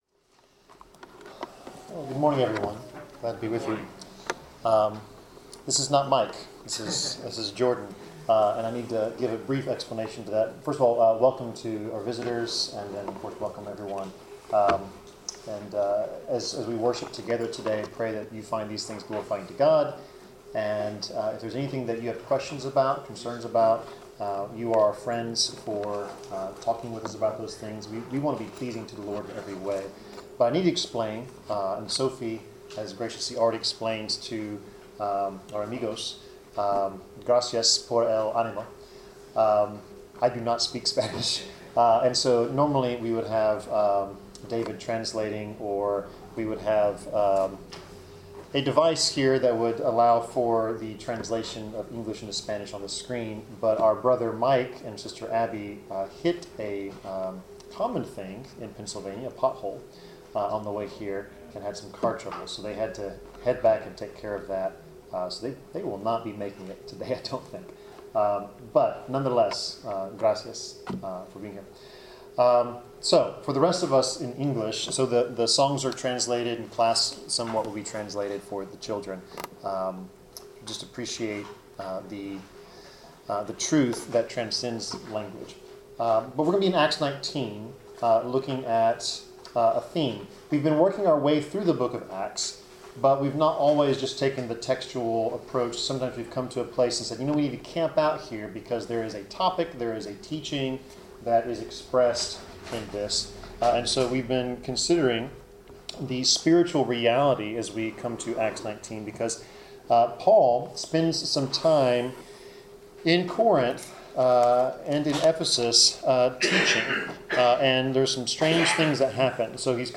Passage: Acts 19:11-20 Service Type: Sermon